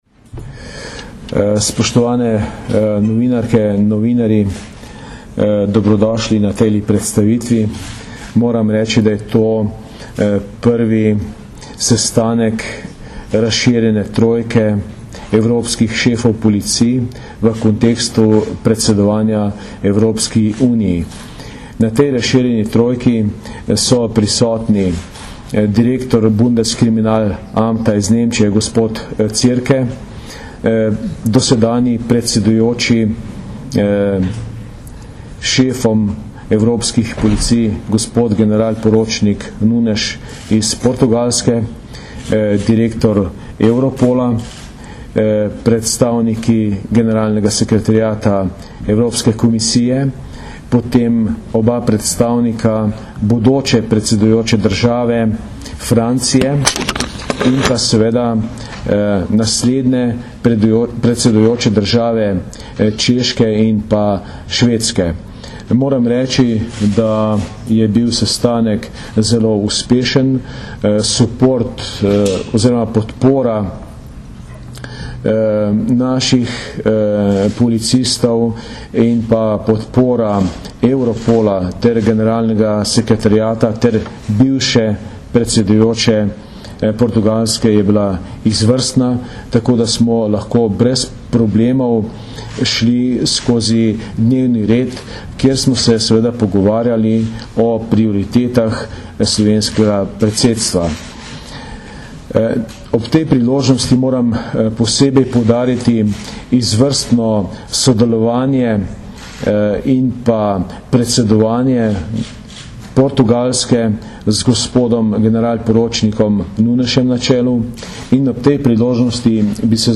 V okviru zasedanja sta generalni direktor slovenske policije Jože Romšek in direktor Europola Max-Peter Ratzel podala tudi izjavo za medije o srečanju in glavnih temah pogovorov.